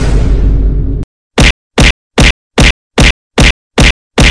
Rock gets mad and punches Sound Button: Unblocked Meme Soundboard